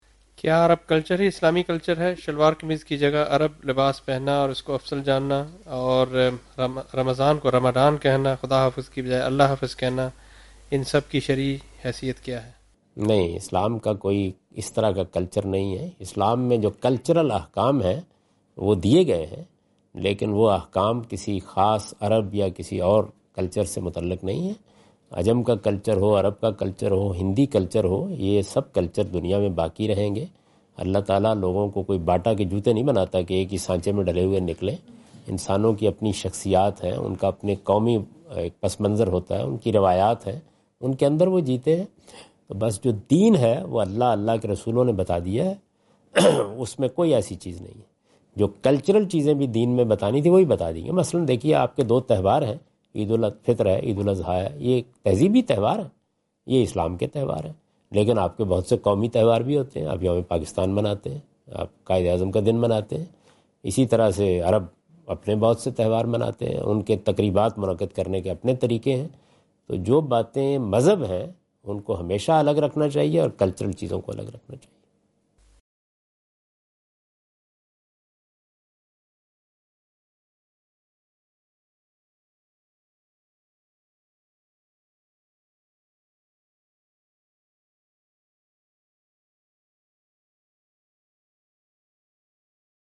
Javed Ahmad Ghamidi answer the question about "Is Arab Culture Most Compatible with Islam?" during his Australia visit on 11th October 2015.
جاوید احمد غامدی اپنے دورہ آسٹریلیا کے دوران ایڈیلیڈ میں "کیا عرب کلچر اسلامی کلچر ہے؟" سے متعلق ایک سوال کا جواب دے رہے ہیں۔